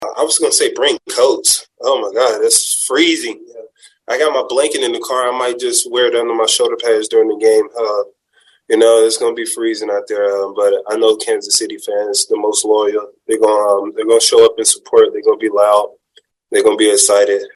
Chiefs defensive lineman Chris Jones has some advice for Chiefs fans.
12-24-chris-jones-bring-coats.mp3